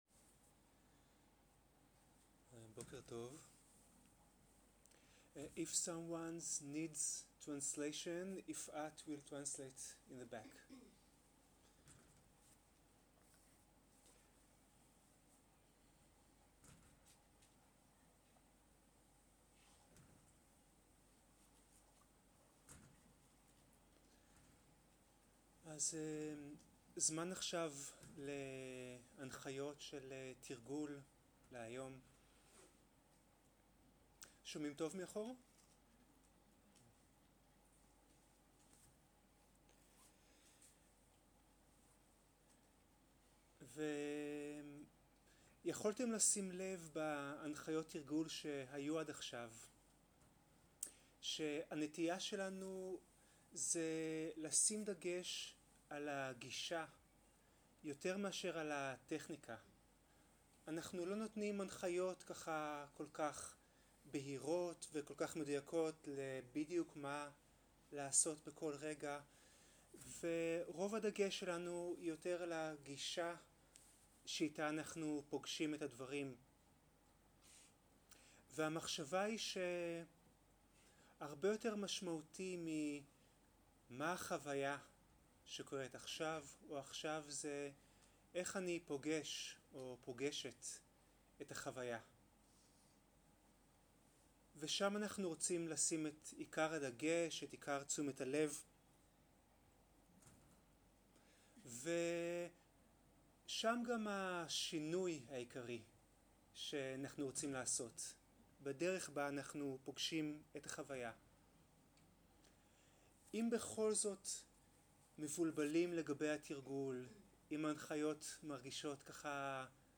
מדיטציה מונחית
סוג ההקלטה: מדיטציה מונחית
איכות ההקלטה: איכות גבוהה